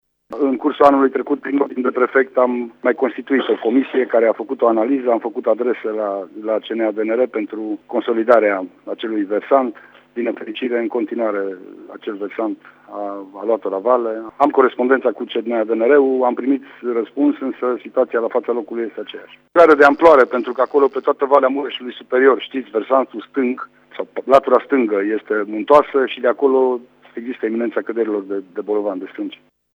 Situația în zonă este cunoscută de anul trecut când a fost sesizată CNADNR, dar încă nu au fost luate măsuri concrete, mai spune prefectul:
prefect-lucrari.mp3